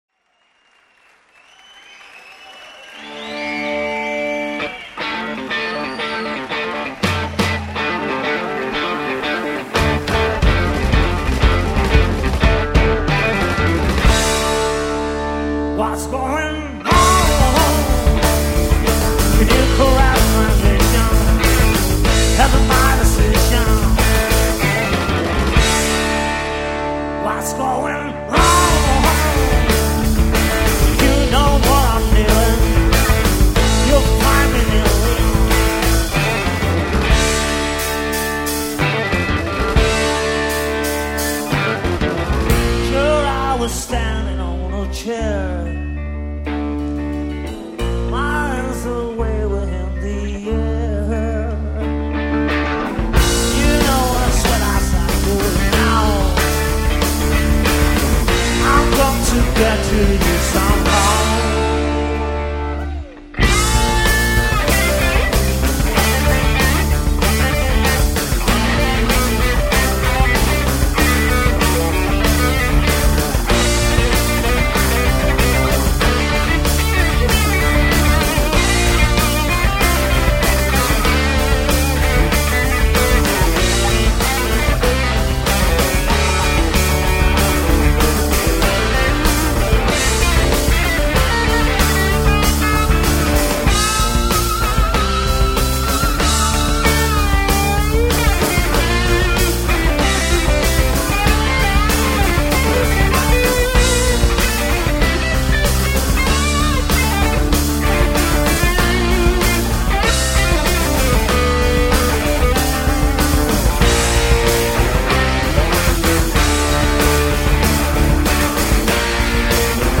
guitar
bass and backing vocals
drums and backing vocals